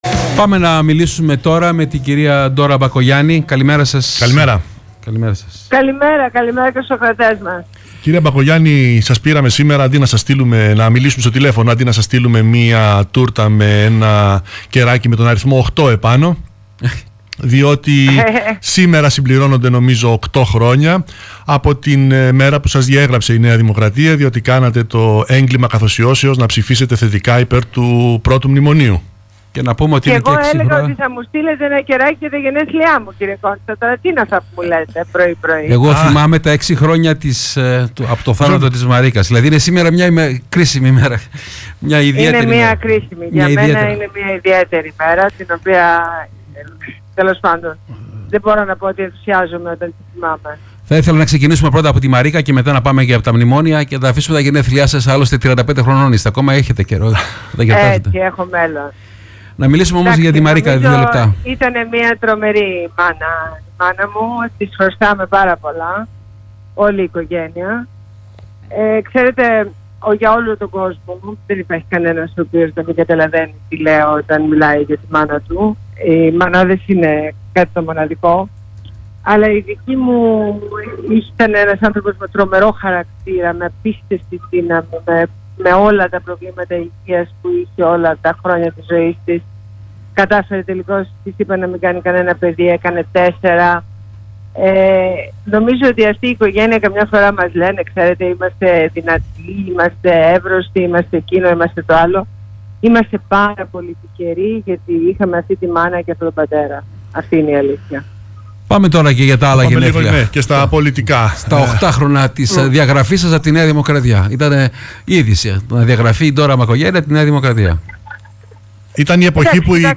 Συνέντευξη στο ραδιόφωνο "Θέμα" στους 104.6